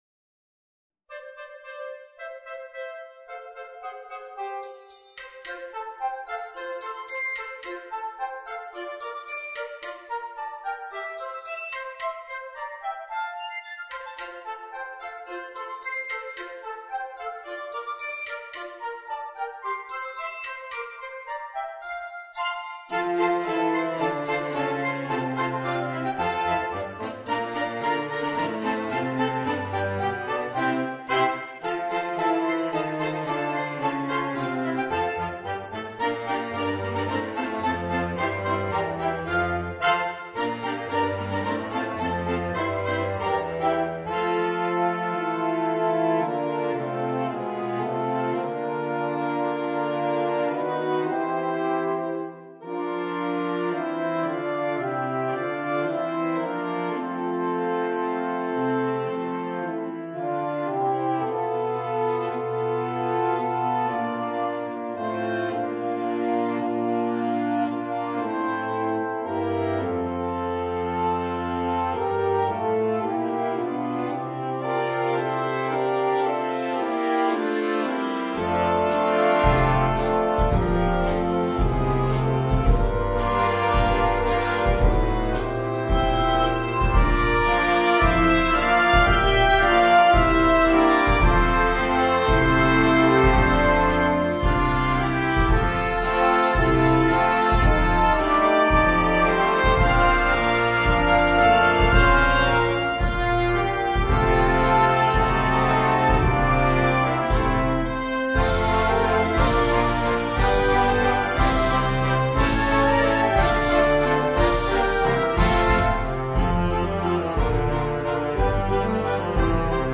Una fantasia di brani natalizi.